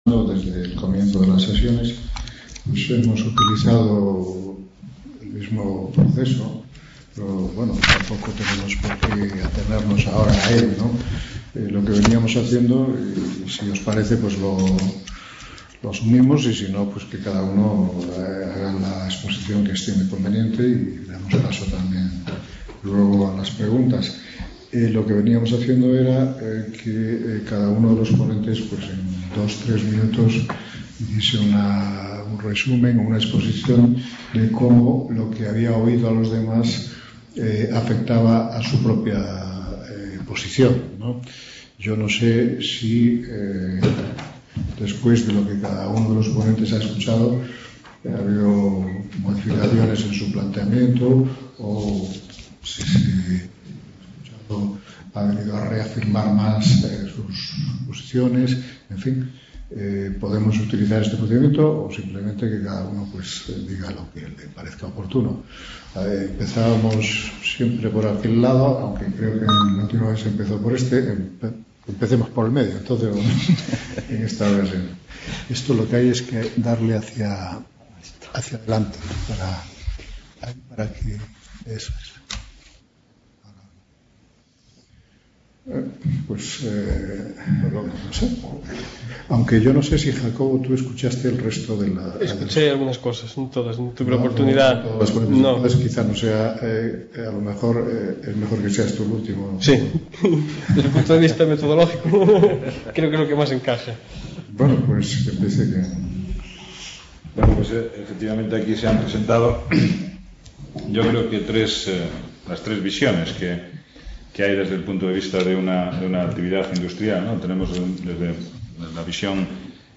Mesa redonda
Simposio sobre Recursos energéticos de la biomasa y del viento
Reunion, debate, coloquio...